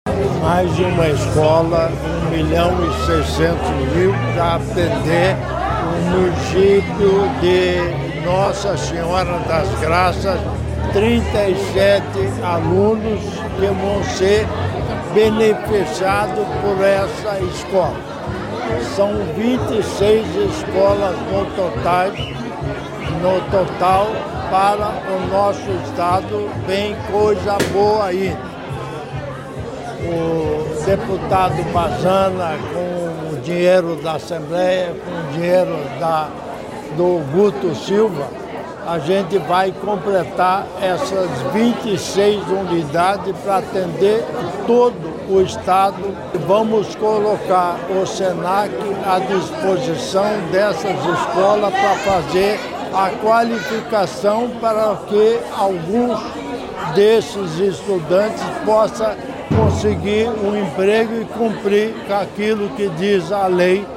Sonora do vice-governador Darci Piana sobre a inauguração da nova Apae de Nossa Senhora das Graças